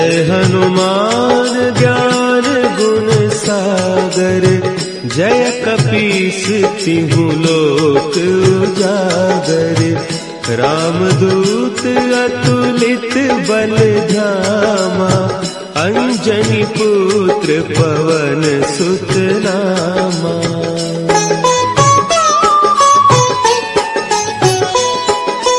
Category: Bhakti Ringtones